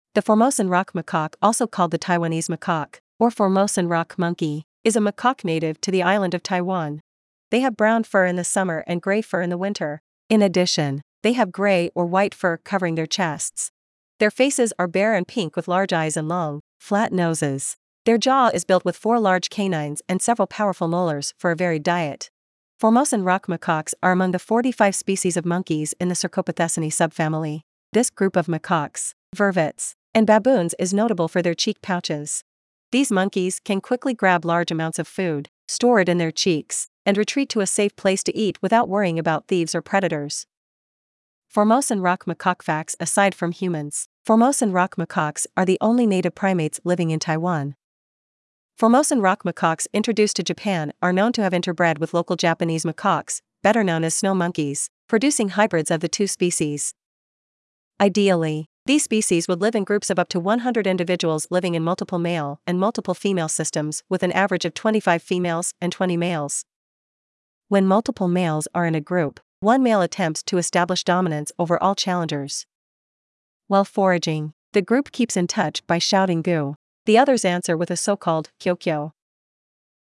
Formosan Rock Macaque
• While foraging, the group keeps in touch by shouting “gu“; the others answer with a so-called “kyaw-kyaw“.
Formosan-Rock-Macaque.mp3